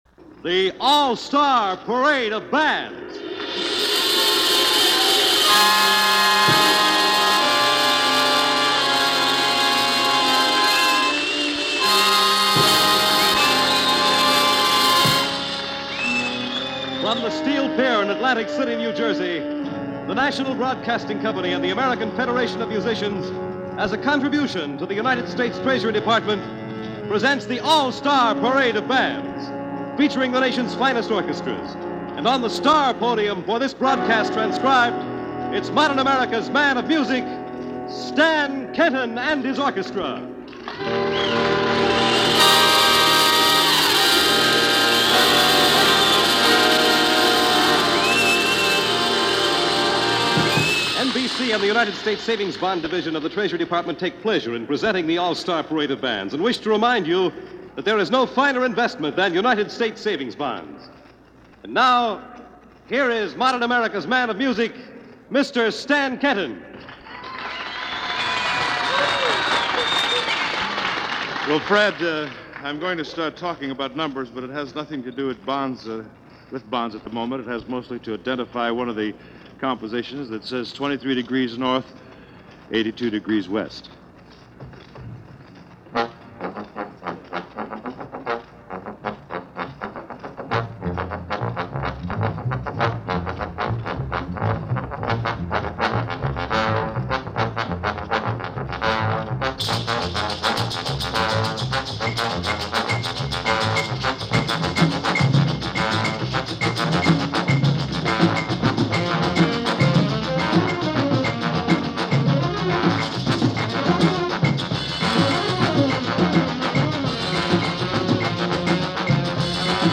live at Steel Pier, New Jersey